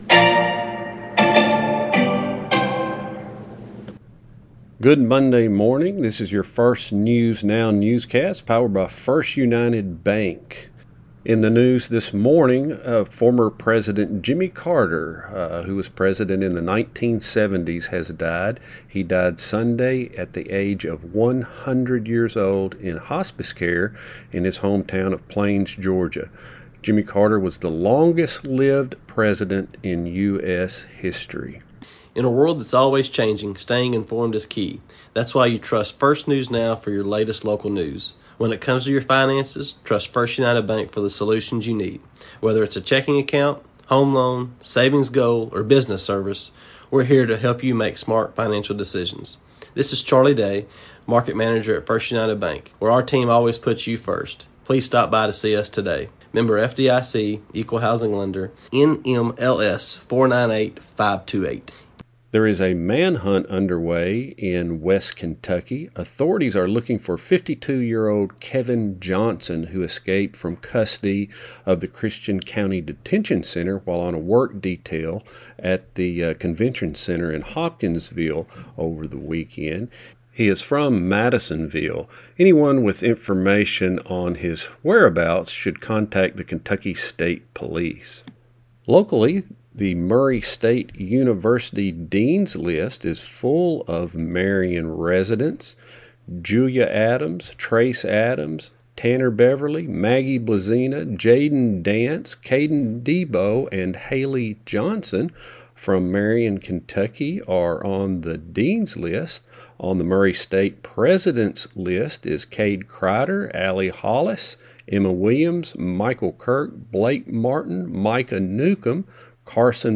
Remember, we produce this newscast Monday, Wednesday, Friday every week